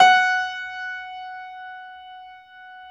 53d-pno16-F3.wav